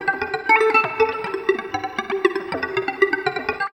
78 GTR 6  -L.wav